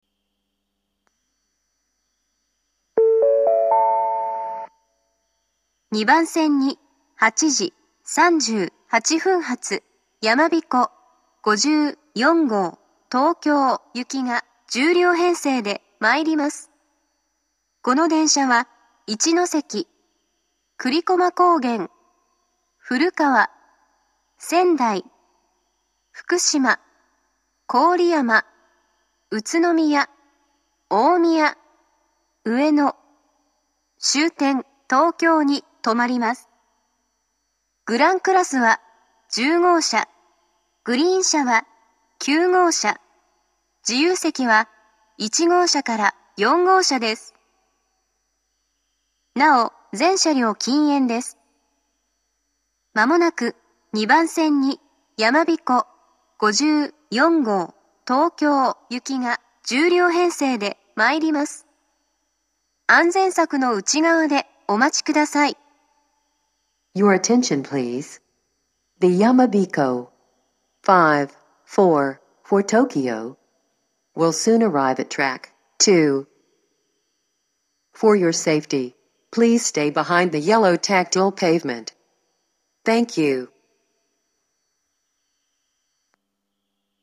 ２０２１年２月上旬頃には、自動放送が合成音声に変更されました。
２番線接近放送